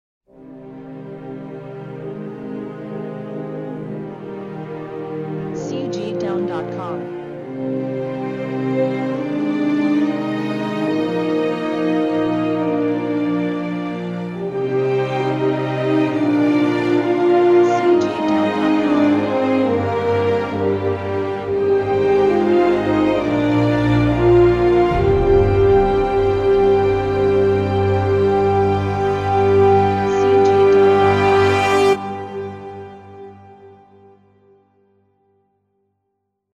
1970-01-01 辉煌感动